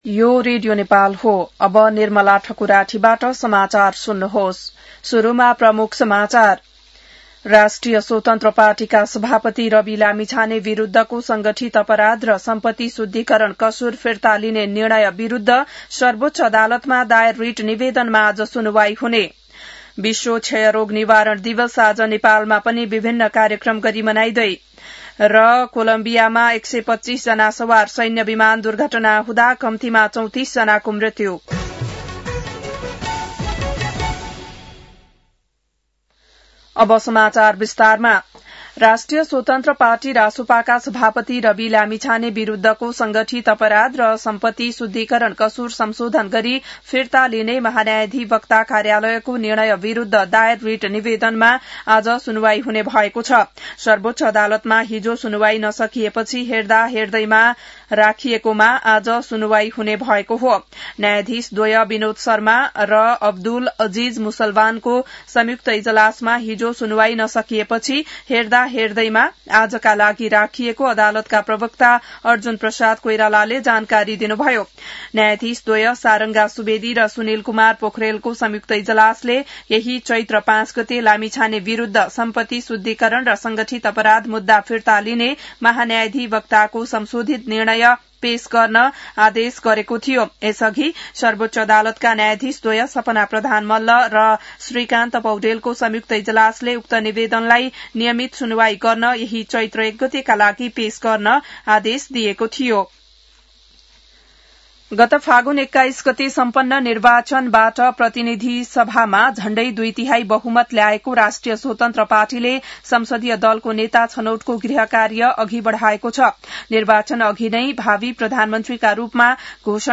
बिहान ९ बजेको नेपाली समाचार : १० चैत , २०८२